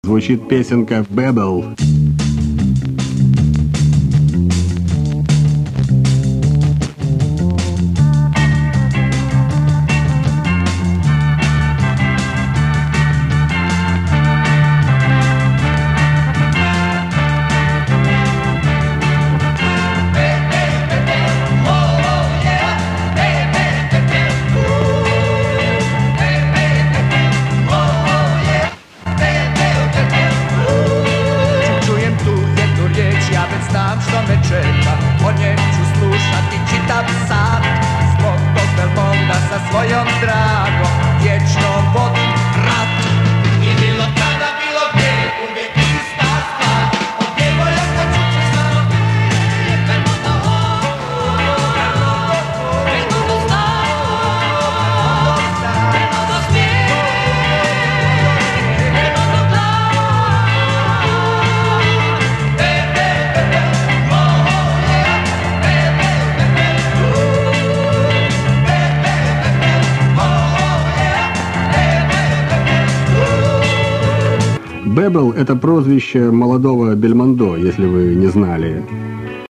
Country:Yugoslavia Released:1968 Genre:Pop Style:Schlager